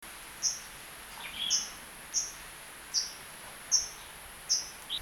Calls
6 May 2012 Po Toi (1063)